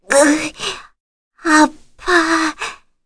Leo-Vox_Dead_kr_01.wav